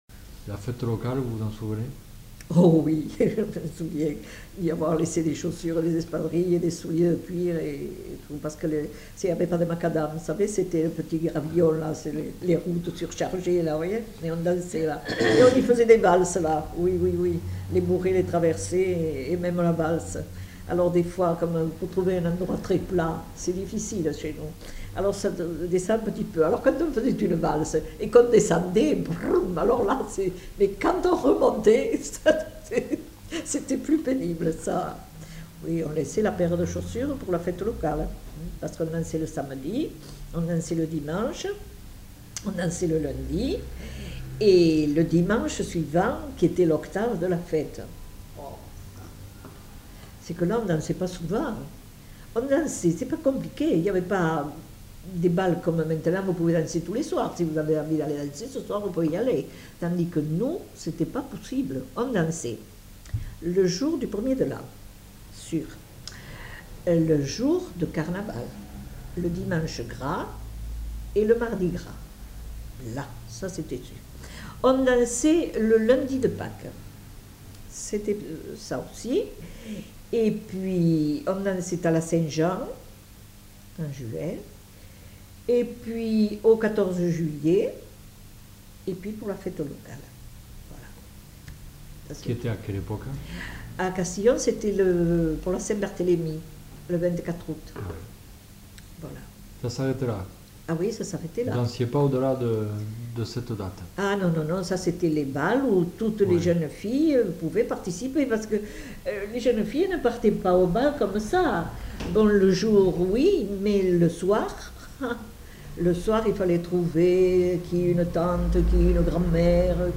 Aire culturelle : Couserans
Genre : témoignage thématique